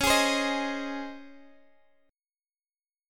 C#M7sus2 Chord